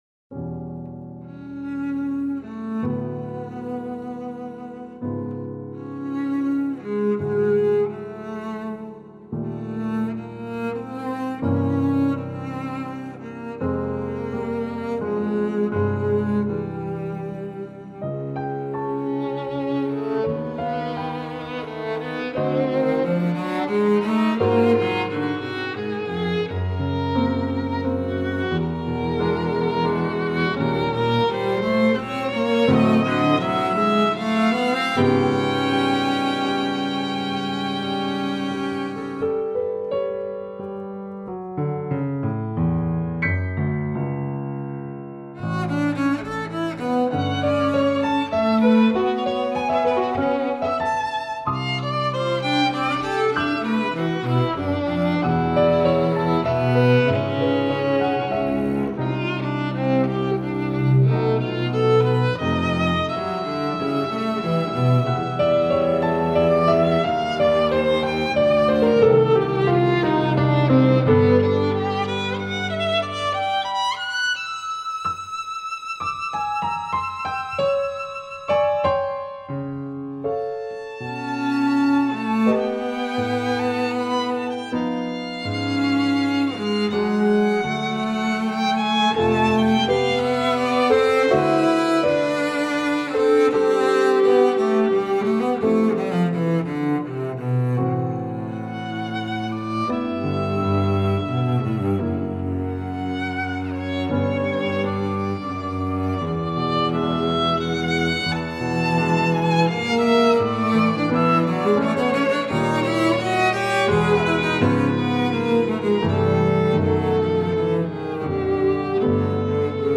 The summer of 2015 I went into Tempest studio.
I recorded the first two movements of my original piano trio.
violin
cello
piano